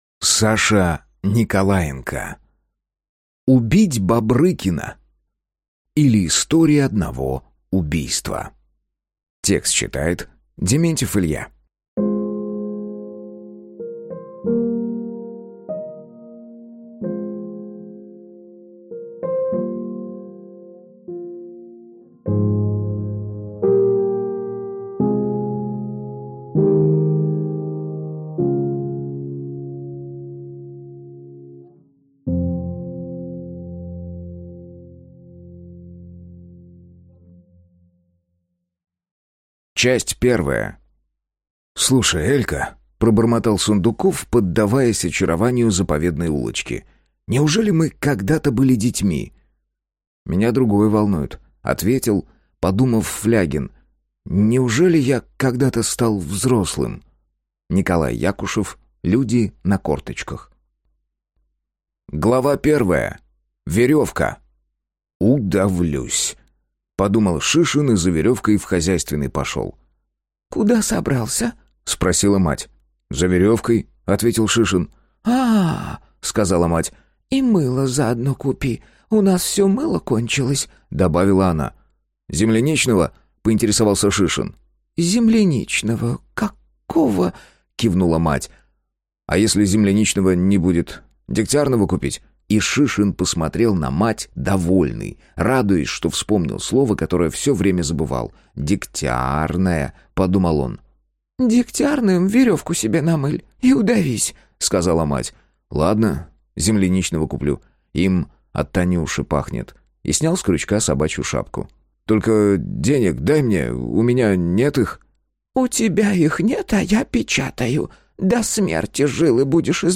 Аудиокнига Убить Бобрыкина, или История одного убийства | Библиотека аудиокниг